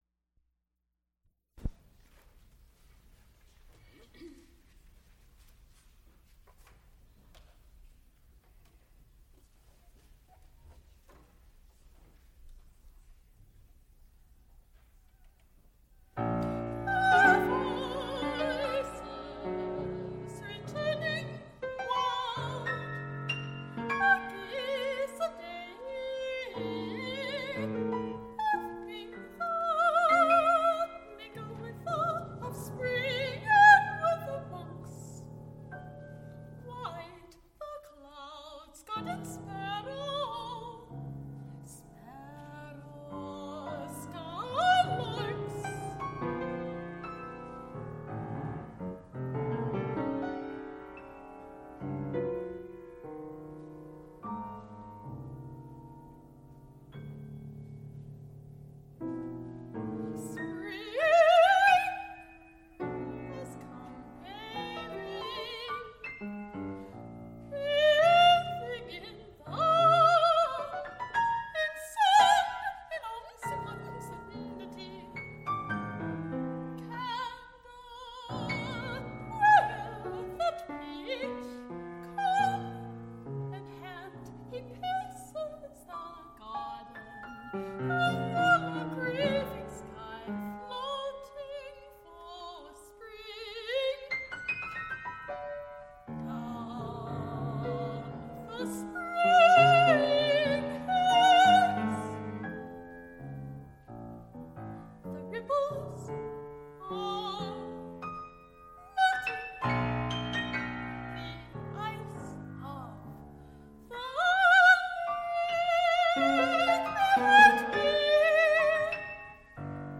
soprano
musical performances
Instrumental ensembles Folk songs, Persian